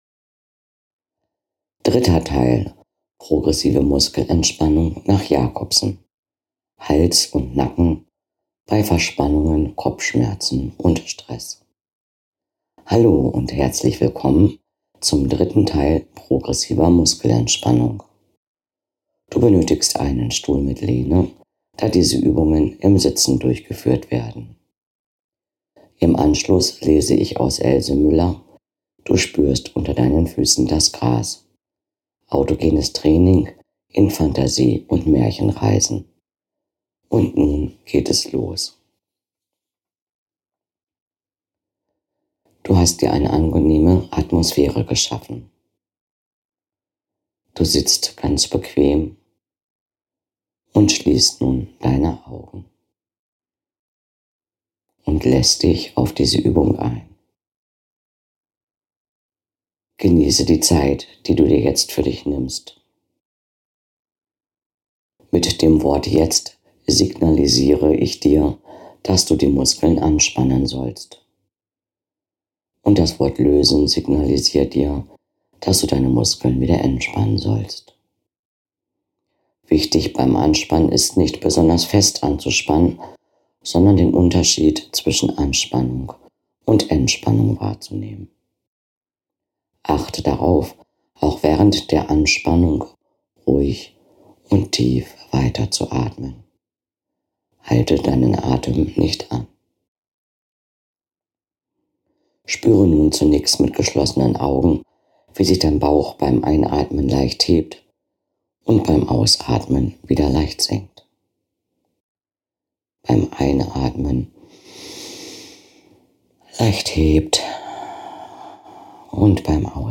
Teil 3: Hals, Nacken und Schultern (bei Verspannungen und Stress) - Progressive Muskelentspannung nach Jacobson im Sitzen